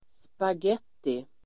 Ladda ner uttalet
Uttal: [spag'et:i]